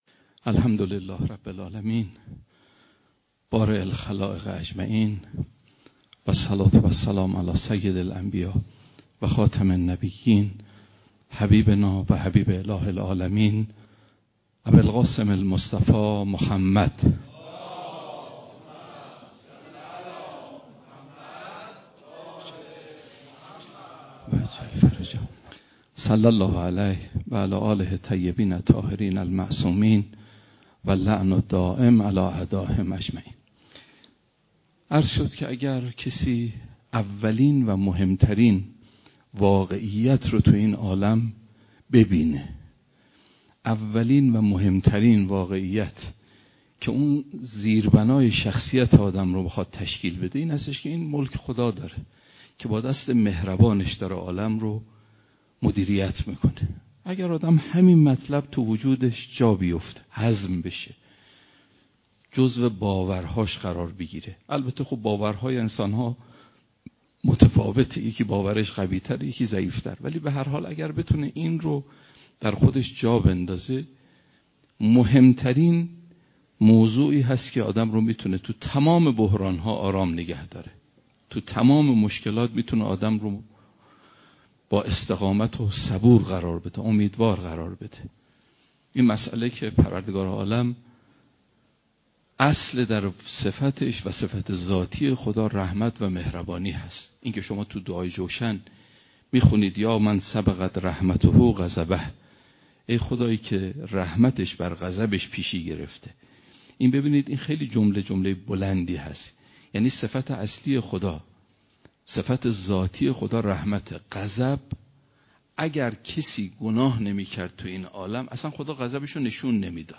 دانلود صوت بیانات معرفتی